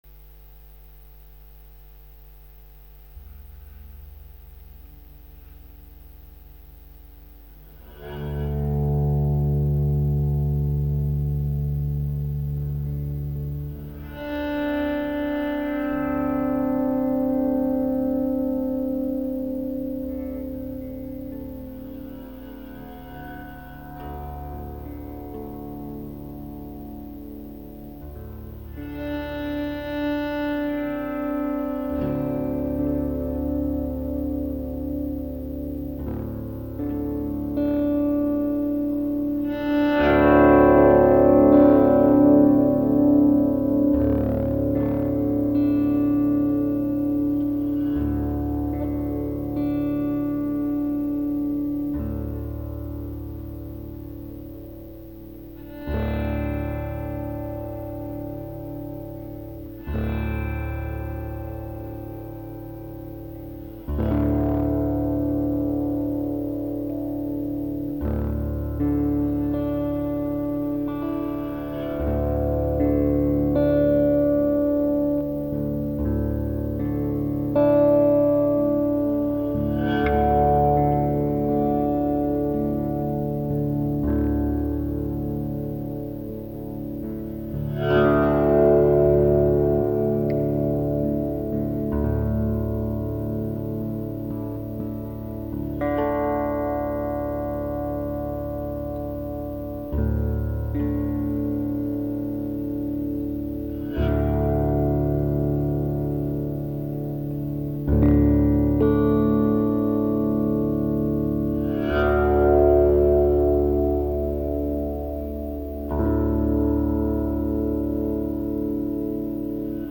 This music has been played using only tradizional instruments, with the purpose to diffuse natural vibrations.
Adding the Indian Sitar, the native Didgeridoo, the violin and the classic guitar, it forms a wave, a vibration, which will accompany you during Reiki treatments or meditations.
musica reiki.mp3